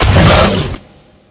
combat / enemy / dulfhit3.ogg